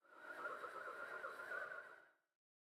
Minecraft Version Minecraft Version snapshot Latest Release | Latest Snapshot snapshot / assets / minecraft / sounds / mob / breeze / whirl.ogg Compare With Compare With Latest Release | Latest Snapshot
whirl.ogg